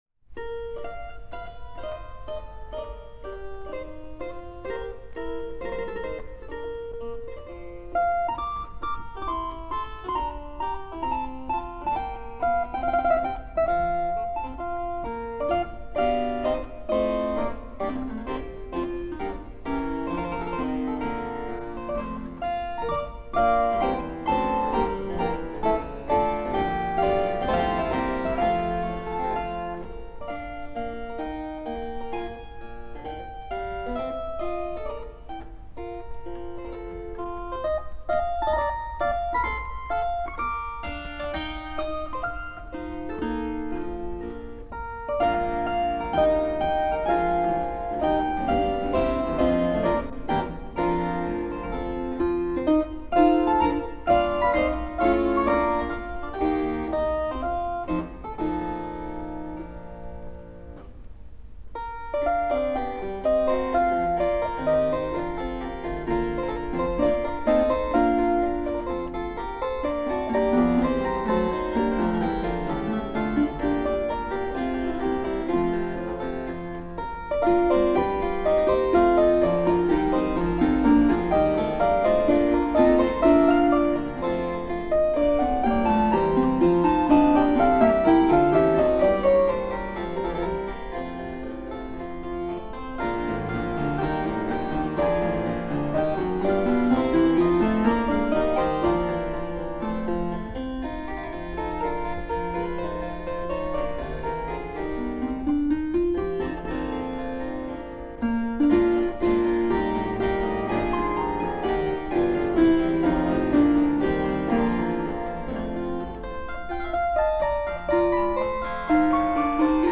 for Four Harpsichords